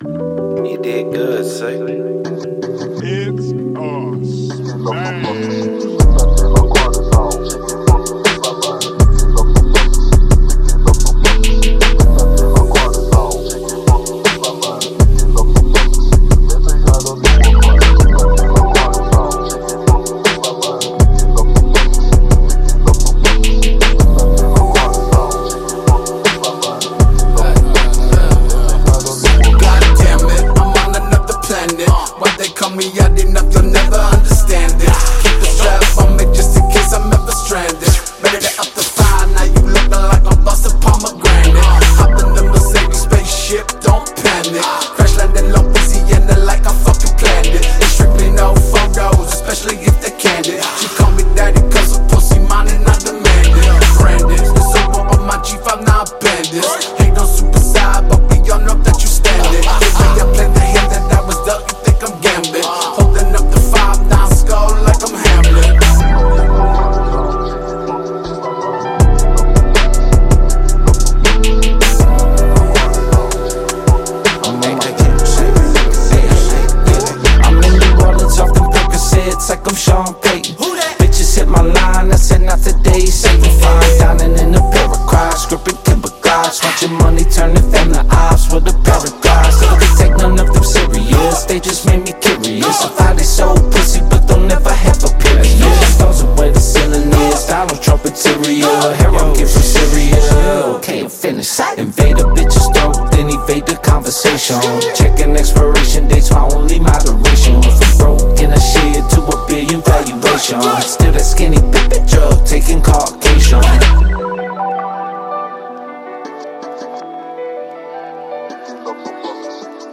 "TRAP METAL"